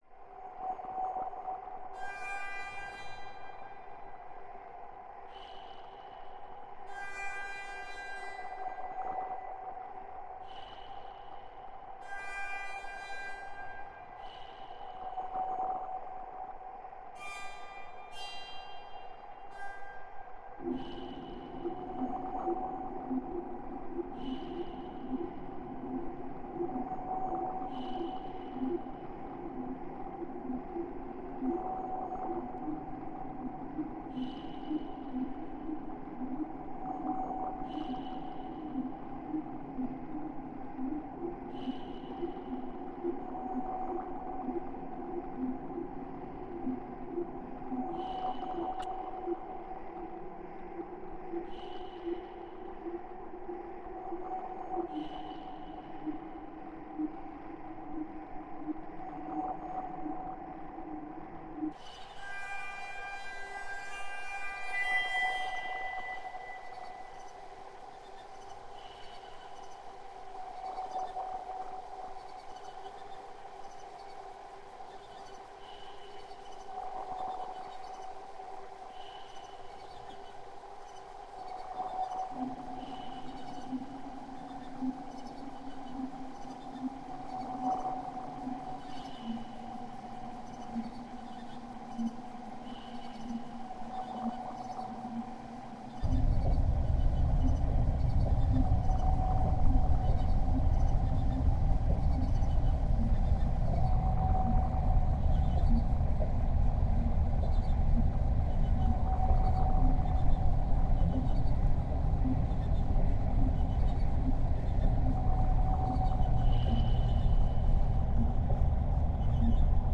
Ambient crickets from Canada